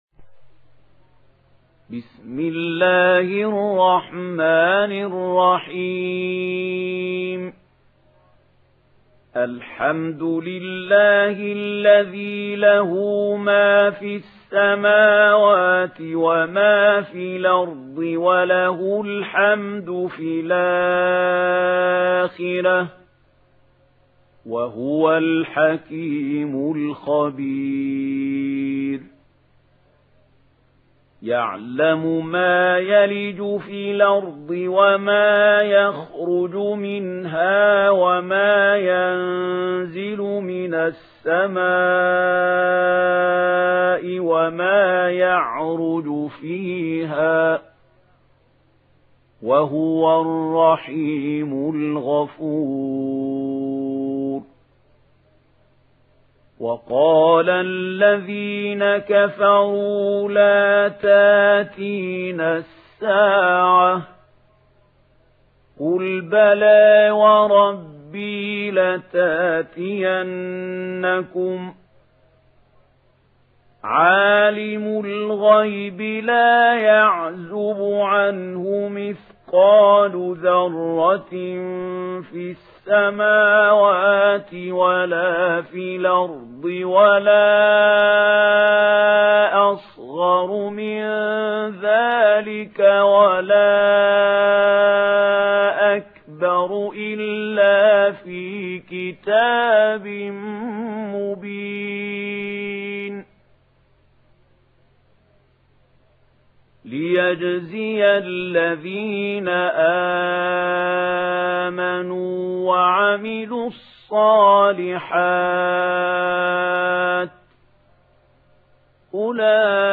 Sourate Saba Télécharger mp3 Mahmoud Khalil Al Hussary Riwayat Warch an Nafi, Téléchargez le Coran et écoutez les liens directs complets mp3